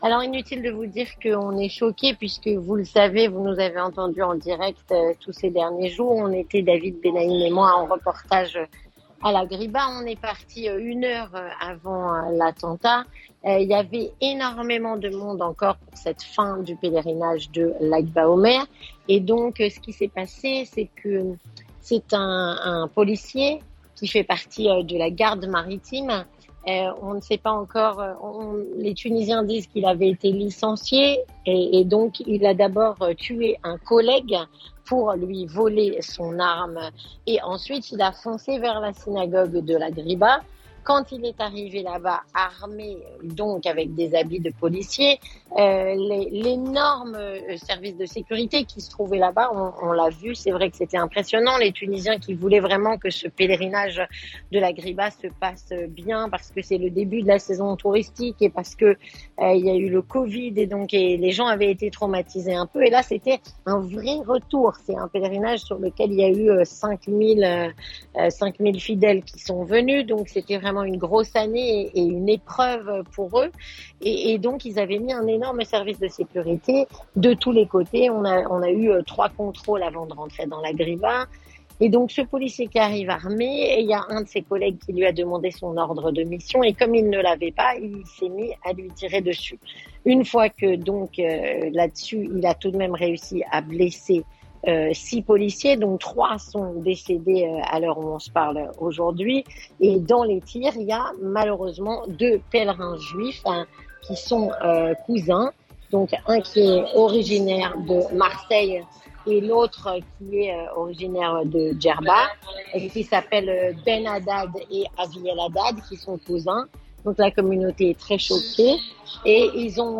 En direct de L'aéroport de Tunis (10/05/2023)